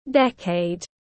Thập kỷ tiếng anh gọi là decade, phiên âm tiếng anh đọc là /ˈdek.eɪd/